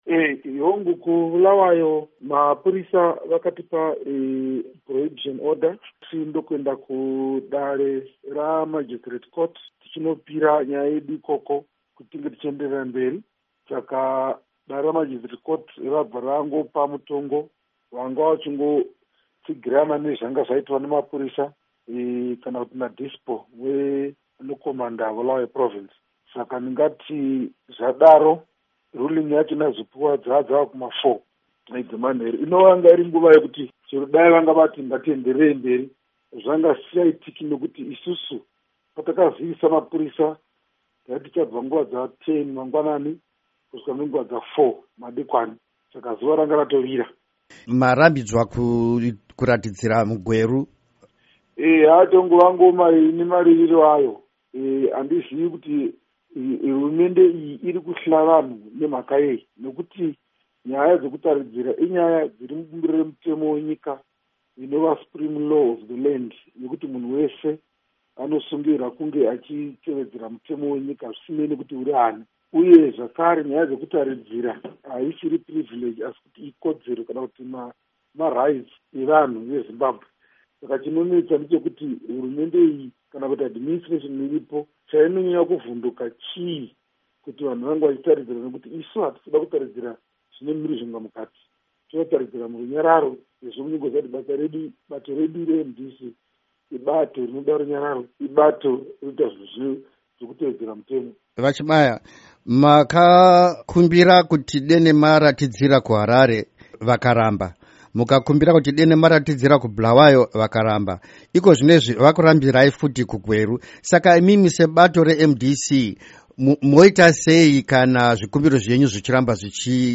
Hurukuro naVaAmos Chibaya